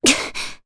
Maria-Vox_Damage_jp_01.wav